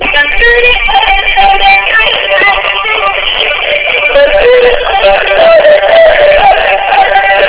踊りフェスタ９８ やはり、大きなモノといえば、三滝通りで行われていたイベントですが、 夜９時まで行われていた、踊りフェスタ９８では、
odori.wav